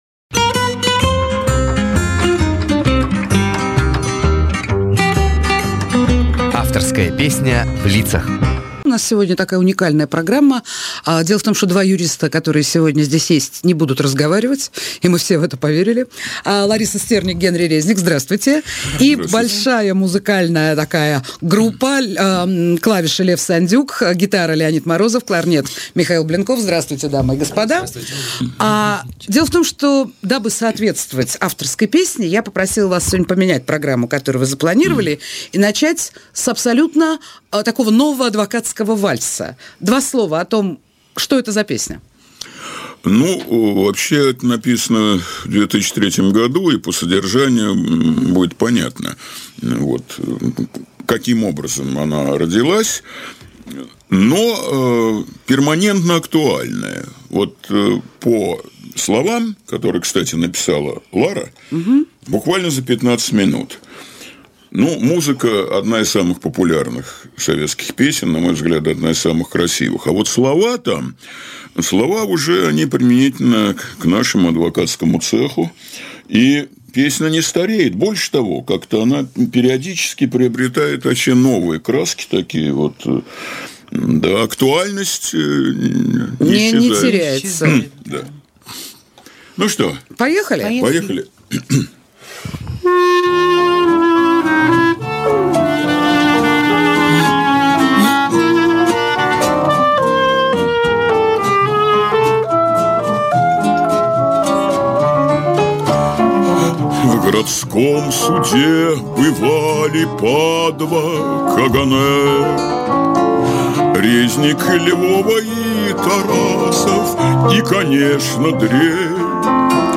Жанр: Авторская песня